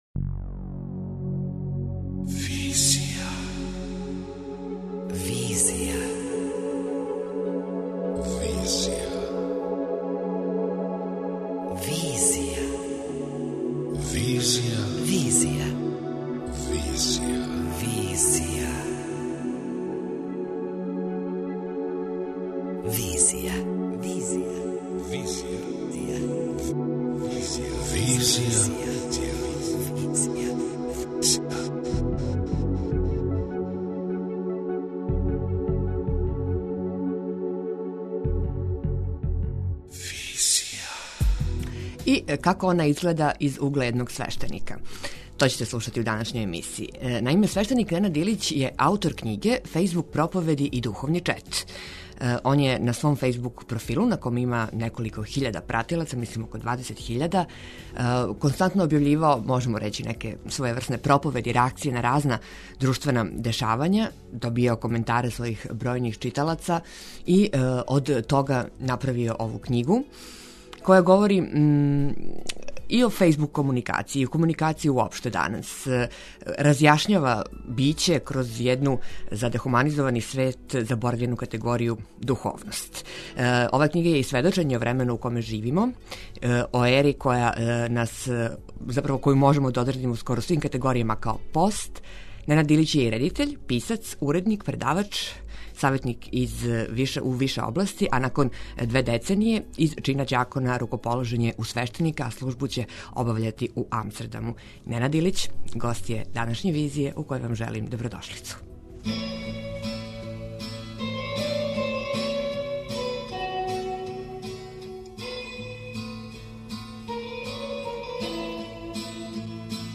преузми : 27.53 MB Визија Autor: Београд 202 Социо-културолошки магазин, који прати савремене друштвене феномене.